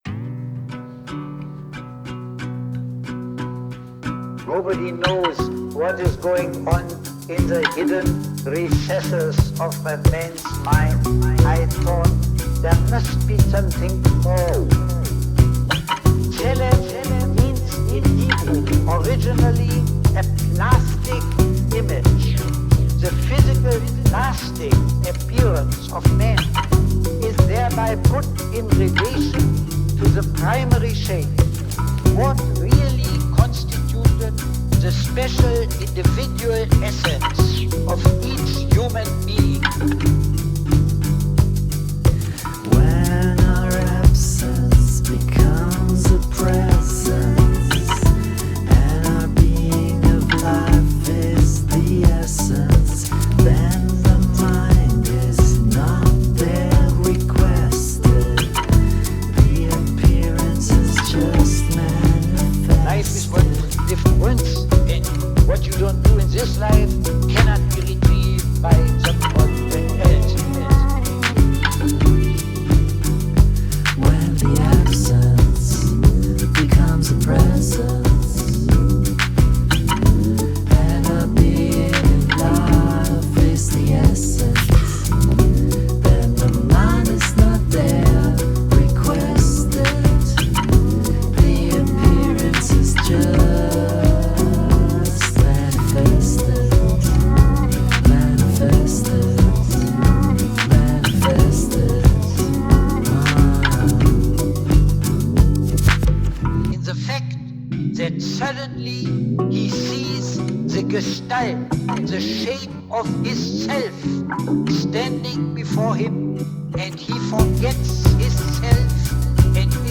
Bass
Vocals & Synths